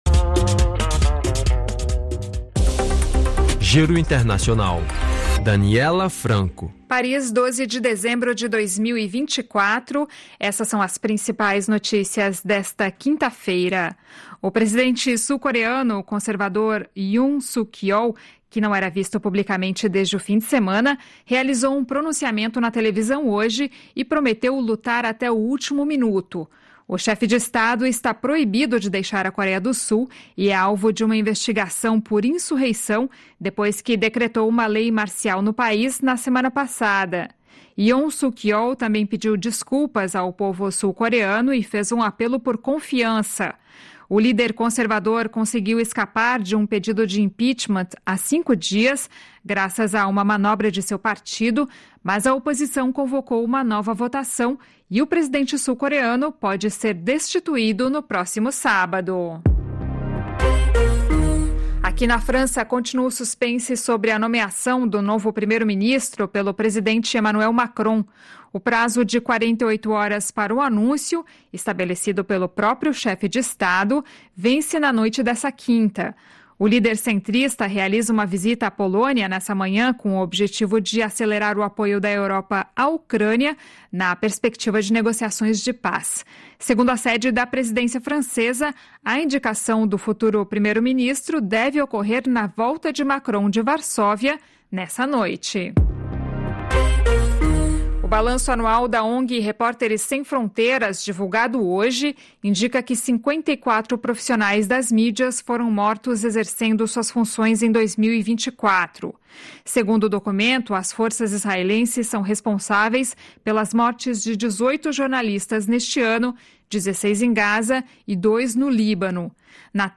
Flash de notícias